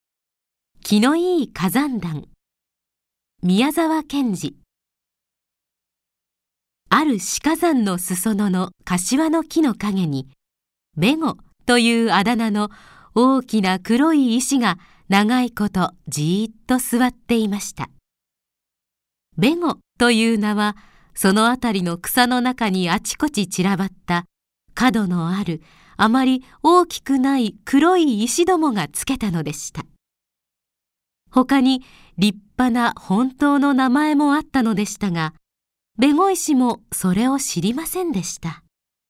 朗読ＣＤ　朗読街道141
朗読街道は作品の価値を損なうことなくノーカットで朗読しています。